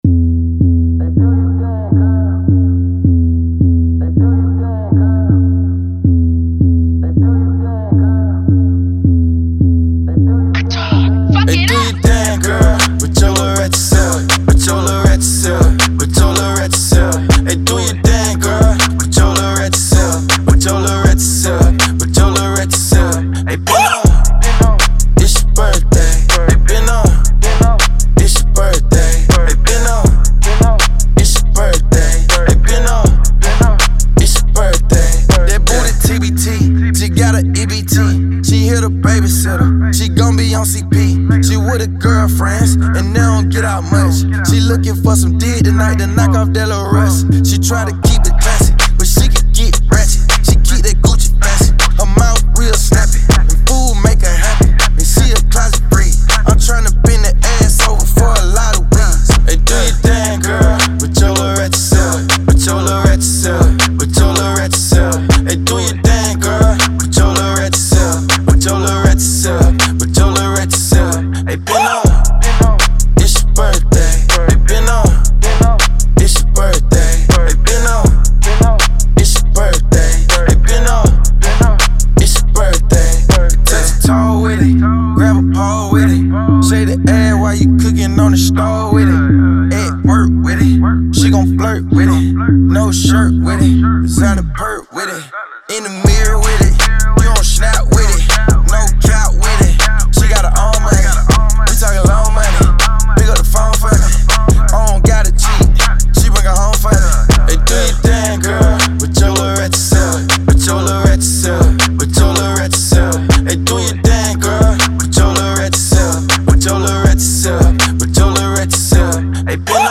Hiphop
bass-heavy, twerk-anthem